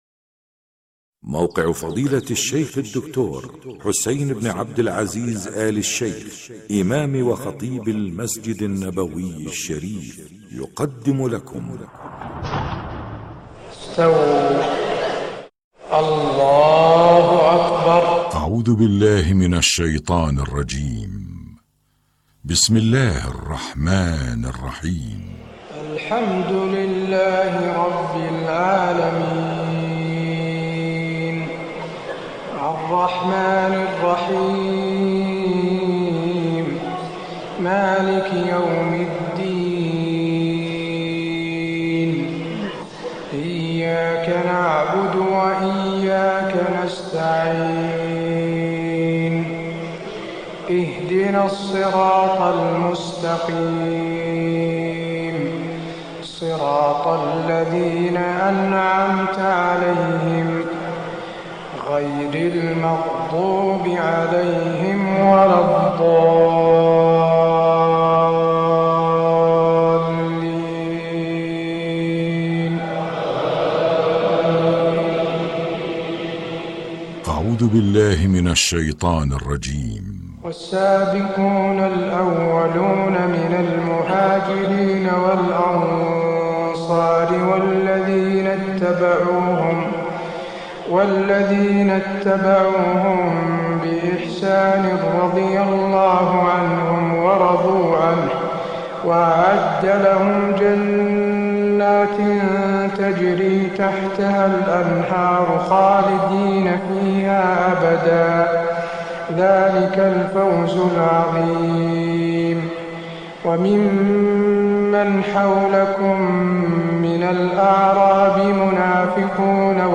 تهجد ليلة 28 رمضان 1425هـ من سورة التوبة (100-121) Tahajjud 28 st night Ramadan 1425H from Surah At-Tawba > تراويح الحرم النبوي عام 1425 🕌 > التراويح - تلاوات الحرمين